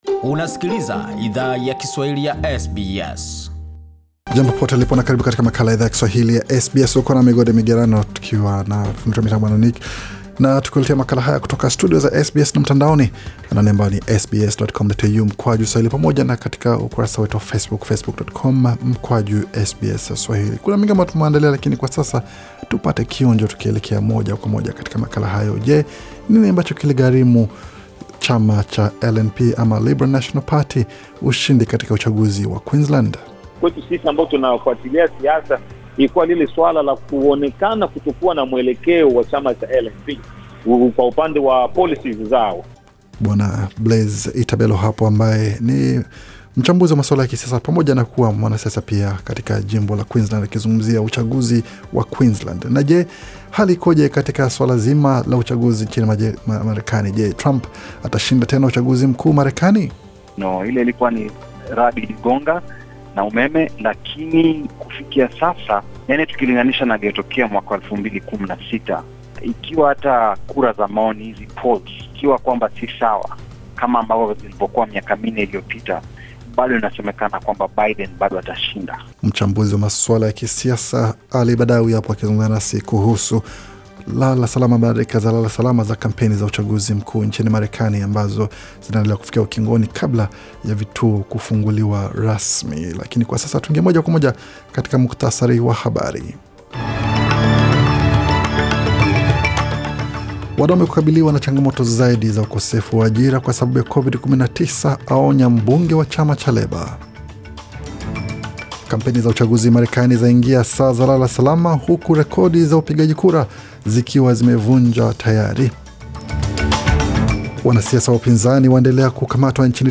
Taarifa ya habari 3 Novemba 2020